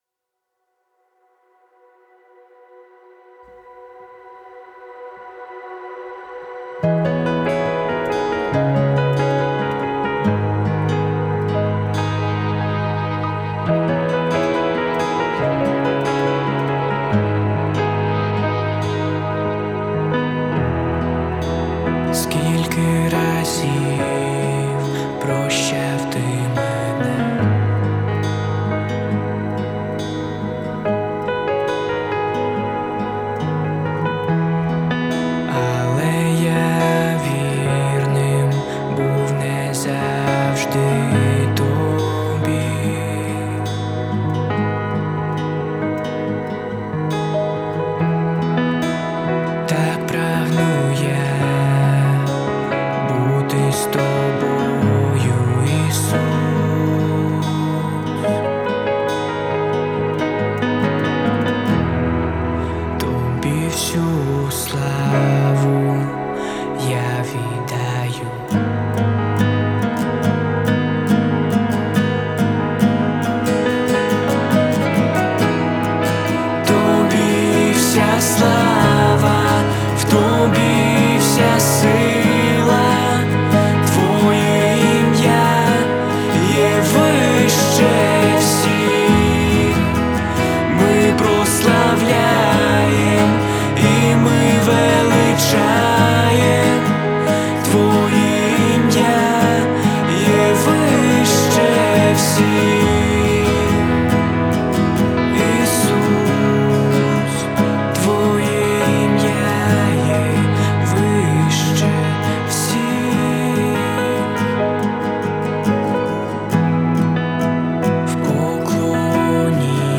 1006 просмотров 413 прослушиваний 103 скачивания BPM: 140